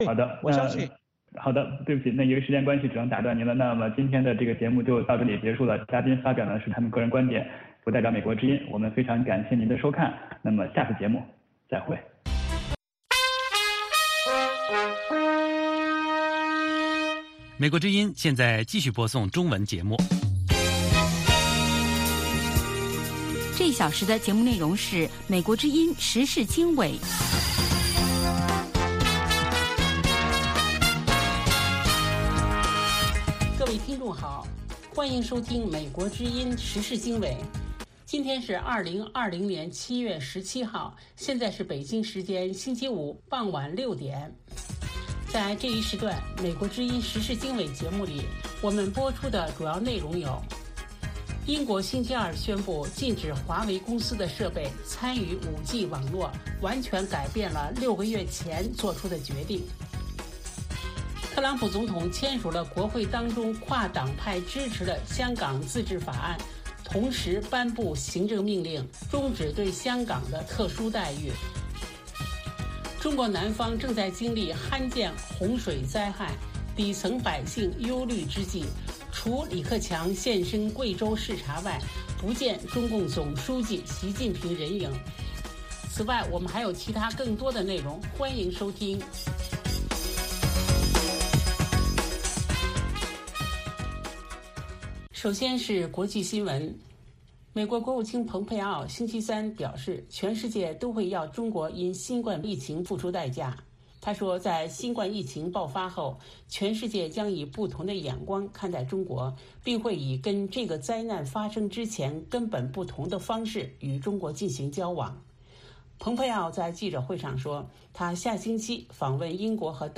美国之音中文广播于北京时间每周一到周五晚上6-7点播出《时事经纬》节目。《时事经纬》重点报道美国、世界和中国、香港、台湾的新闻大事，内容包括美国之音驻世界各地记者的报道，其中有中文部记者和特约记者的采访报道，背景报道、世界报章杂志文章介绍以及新闻评论等等。